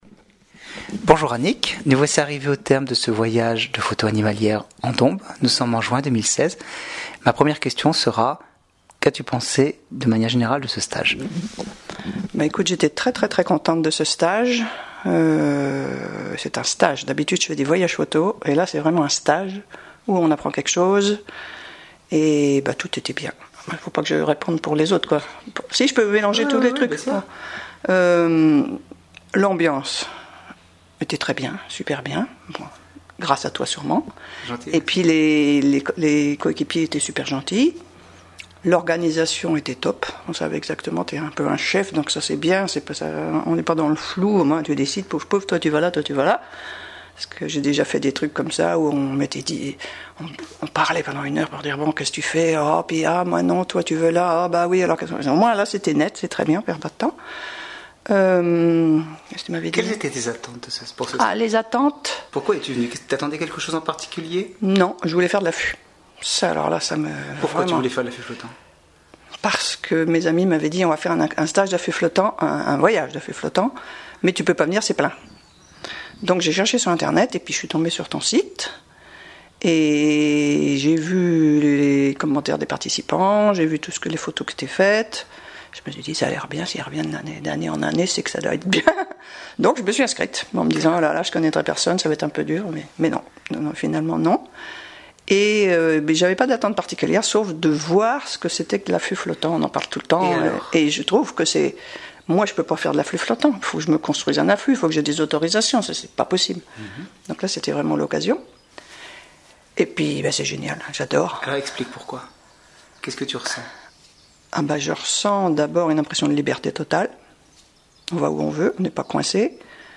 Le commentaire écrit et oral des participants